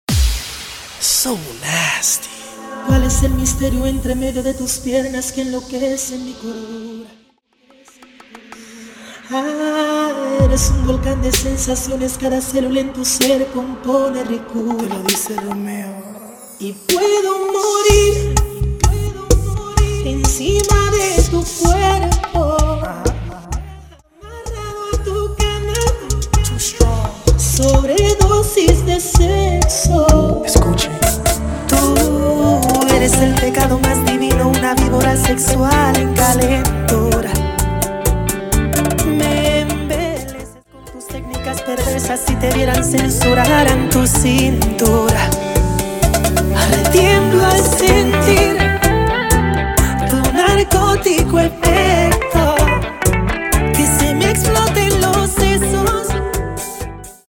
Latin genres